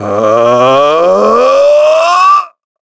Shakmeh-Vox_Casting3_a.wav